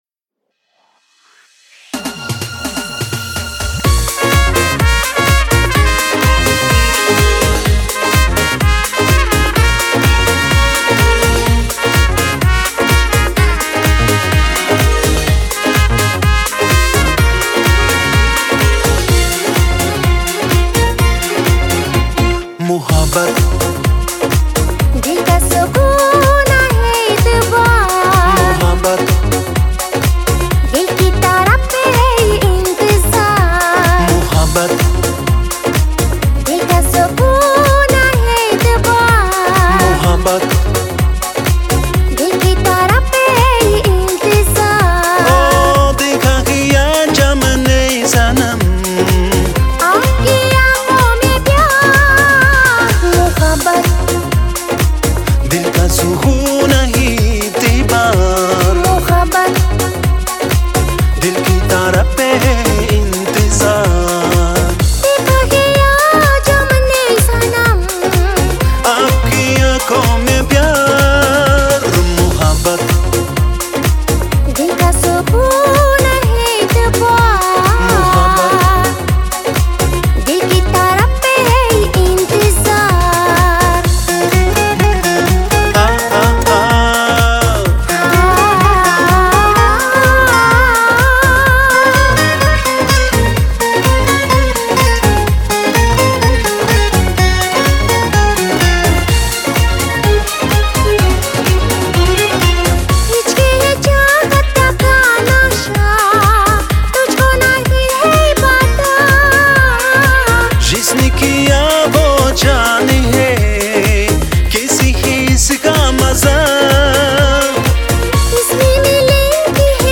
это романтичная песня в жанре поп